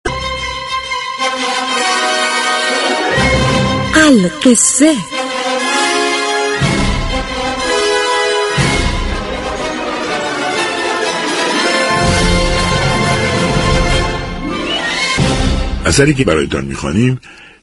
بهروز رضویی روایتگر" القصه" رادیو صبا می شود.
در هفته جاری بهروز رضوی گوینده پیشكسوت رادیو روایتگر مجموعه داستان های زن زیادی به قلم جلال آل احمد برای مخاطبان می شود.